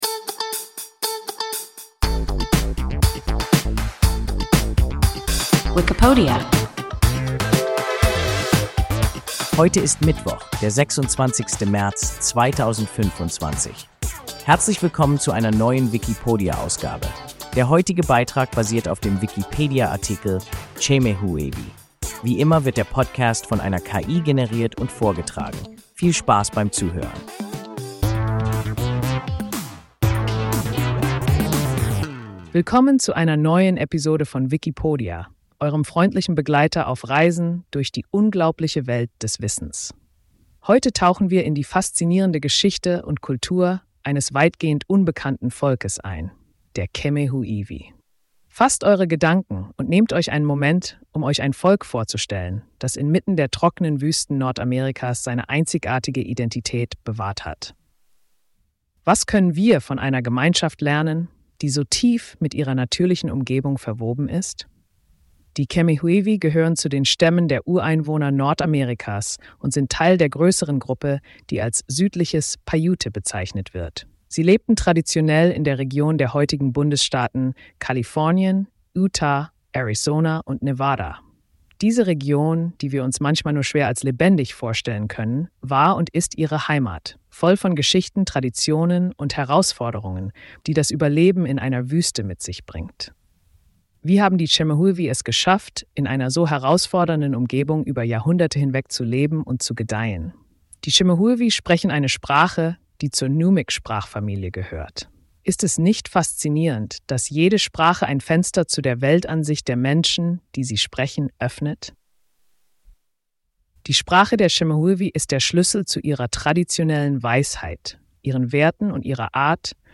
Chemehuevi – WIKIPODIA – ein KI Podcast